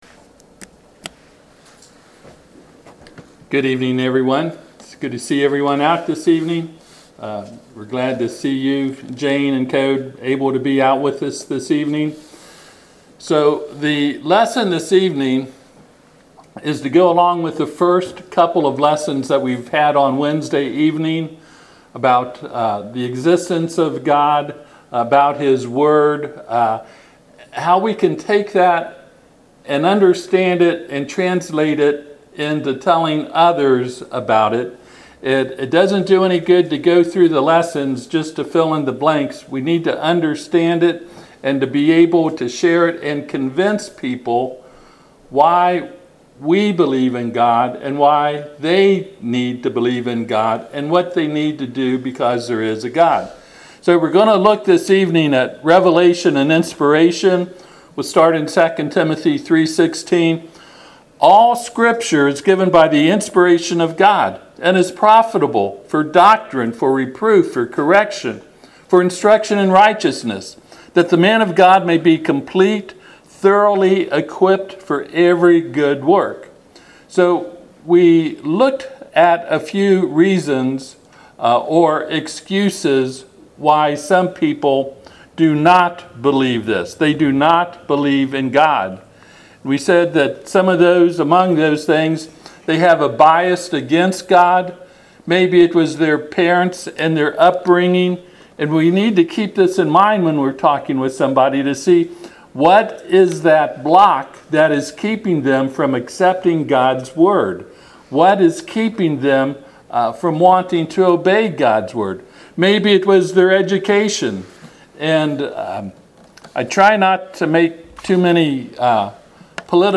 Passage: 2Timothy 3:16-17 Service Type: Sunday PM